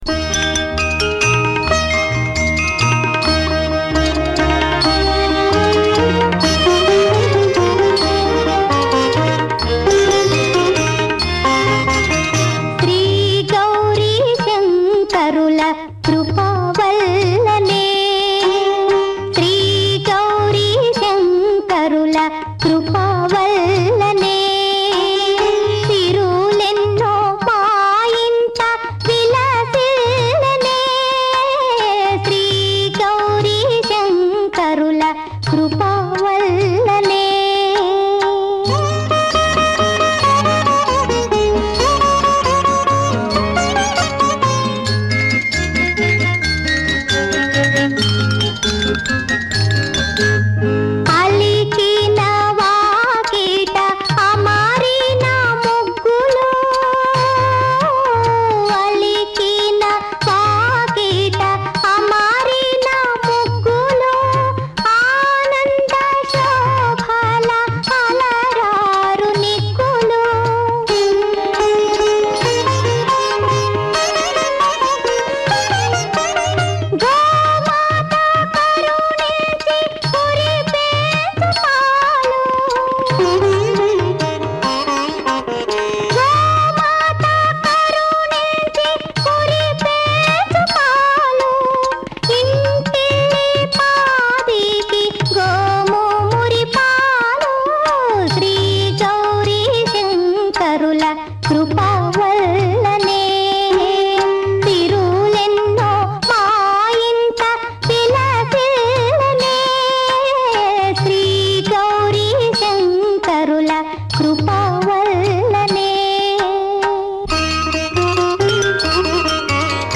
పాడిన ఈ పాట